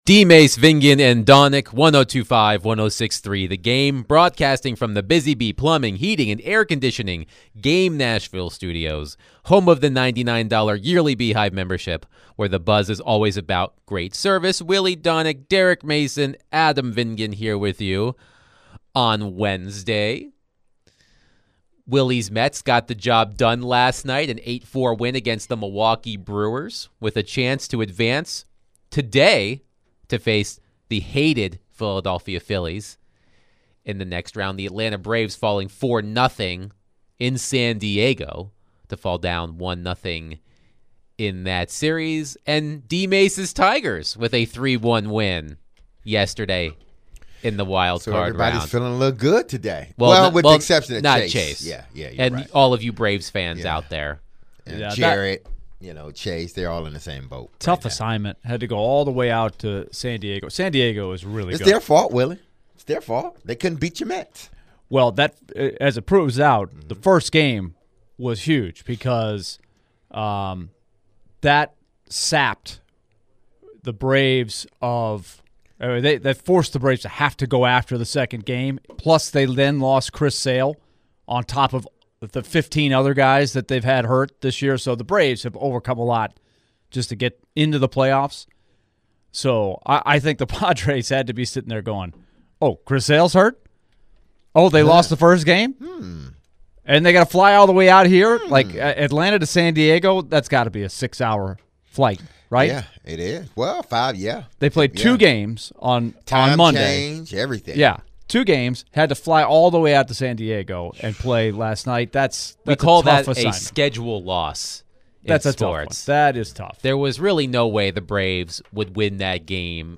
Should Will Levis continue quarterbacking the Titans? Or is this Mason Rudolph’s team going forward? The guys answered some calls and texts surrounding the Titans later in the hour.